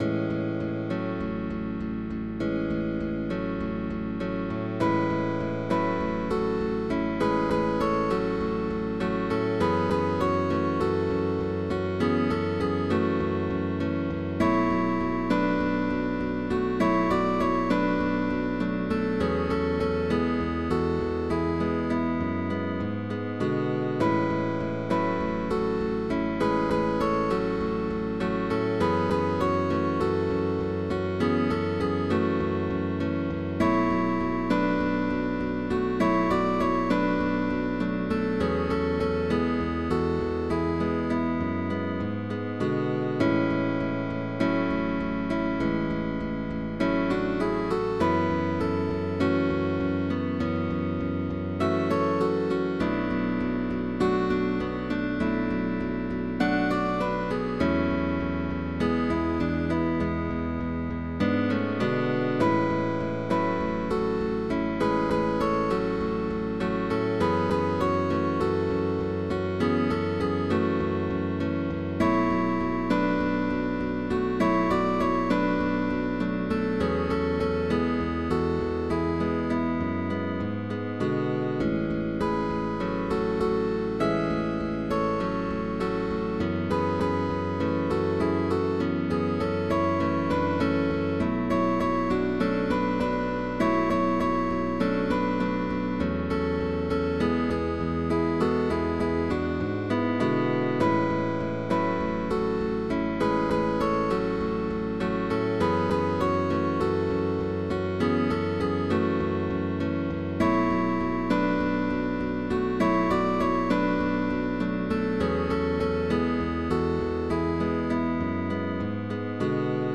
With optional bass
Christmas Pop Songs